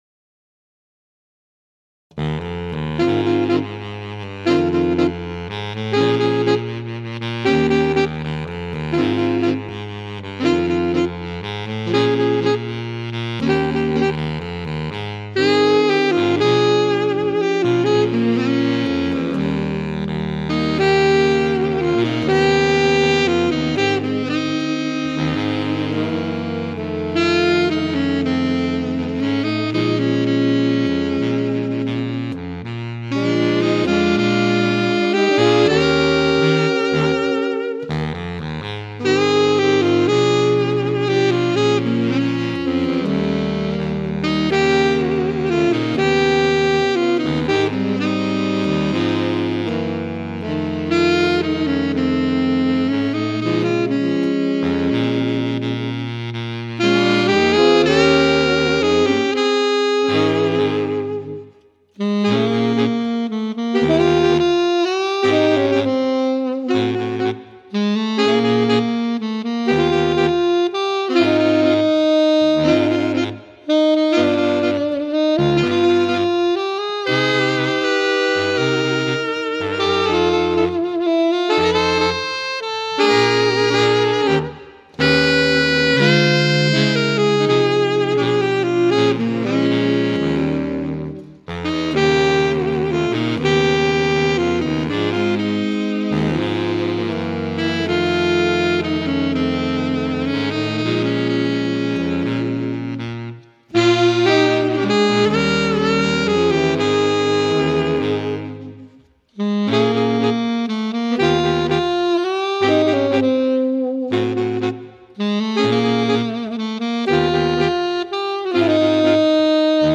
Ranges: Alto 1: F3. Tenor: B3. Baritone: B1
More Saxophone Quartet Music